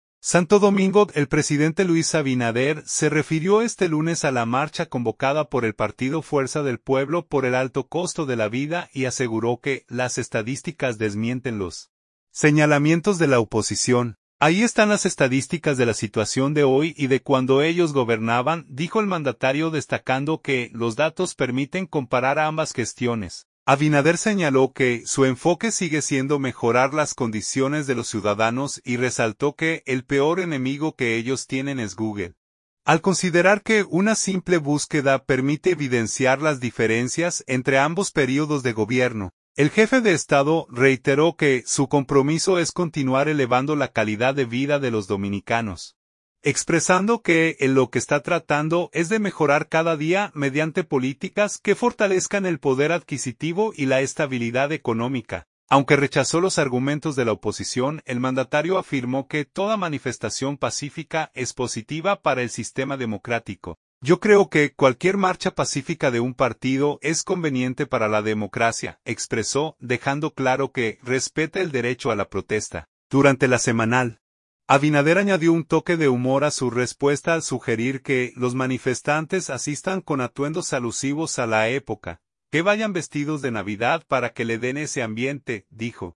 Durante La Semanal, Abinader añadió un toque de humor a su respuesta al sugerir que los manifestantes asistan con atuendos alusivos a la época.